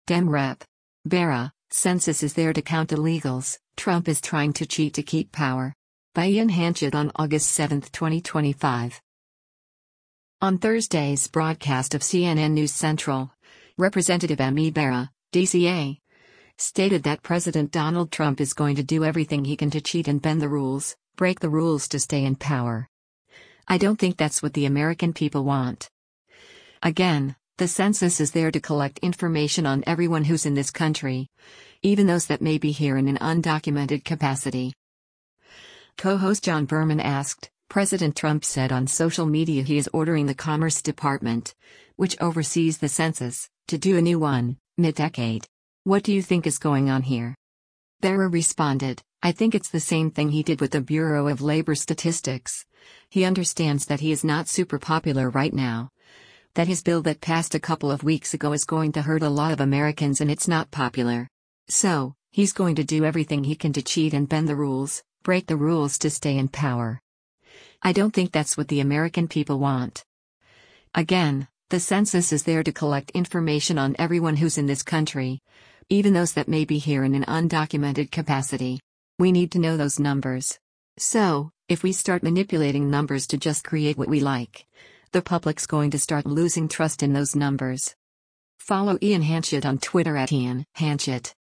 On Thursday’s broadcast of “CNN News Central,” Rep. Ami Bera (D-CA) stated that President Donald Trump is “going to do everything he can to cheat and bend the rules, break the rules to stay in power.
Co-host John Berman asked, “President Trump said on social media he is ordering the Commerce Department — which oversees the Census — to do a new one, mid-decade.